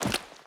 Footsteps / Water / Water Land.ogg